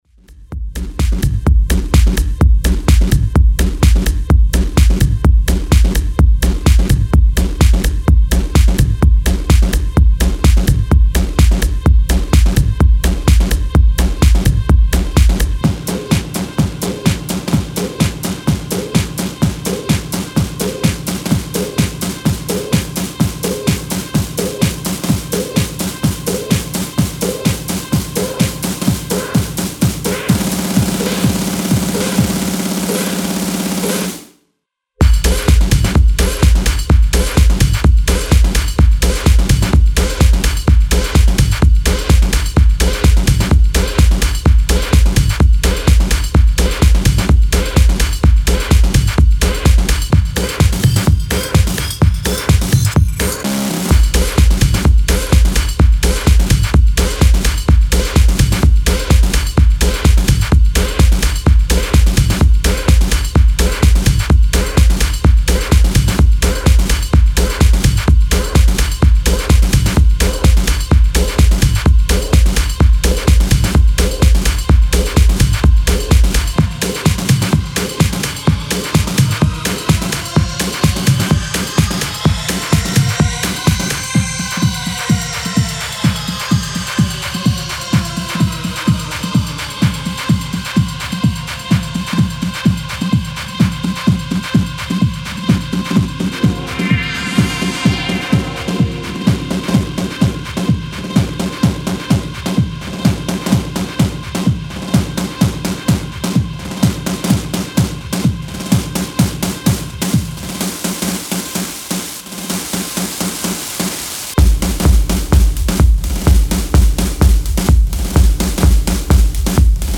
Style: Techno